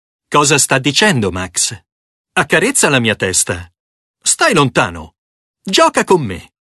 dog
angry